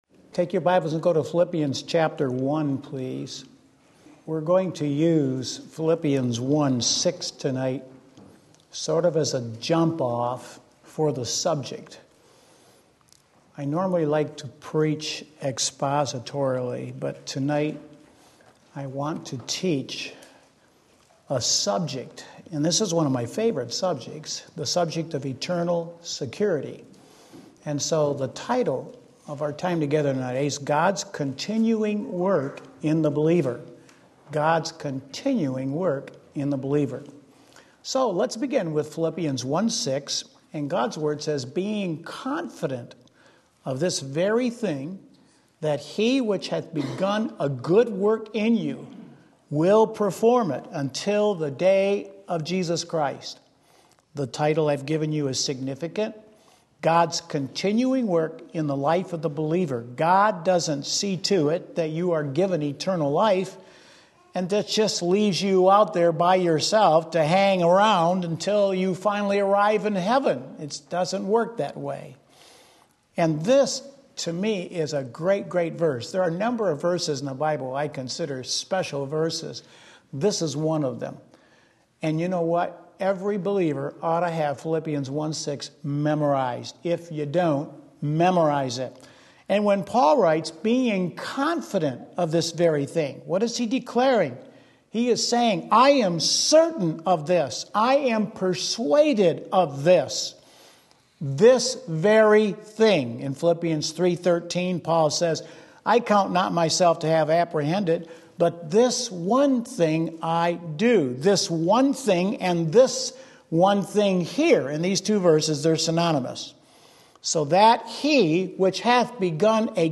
Sermon Link
Wednesday Evening Service